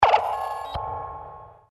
connected.mp3